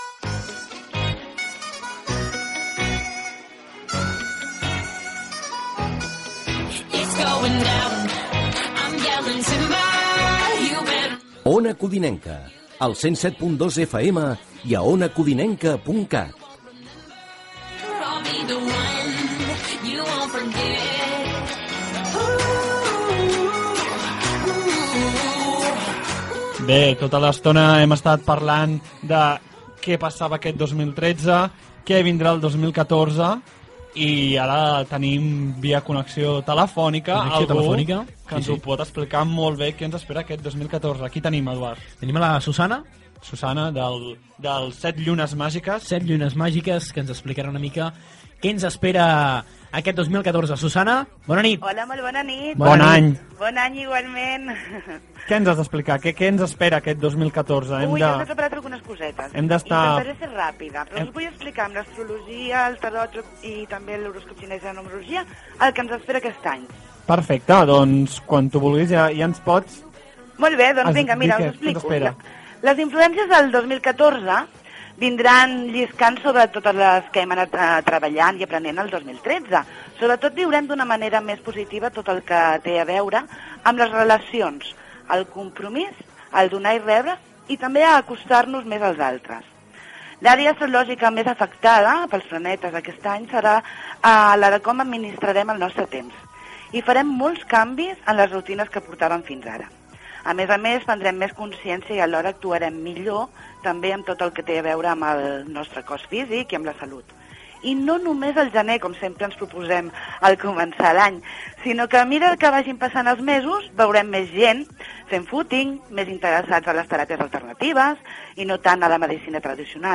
Identificació de l'emissora
Gènere radiofònic Entreteniment